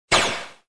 bullet.mp3